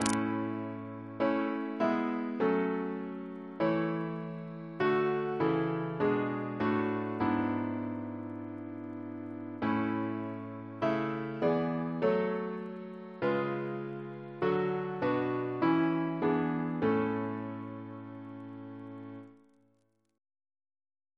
Double chant in A Composer: Thomas Attwood Walmisley (1814-1856), Professor of Music, Cambridge Reference psalters: ACB: 19; ACP: 357; CWP: 24; H1982: S7; OCB: 184; RSCM: 152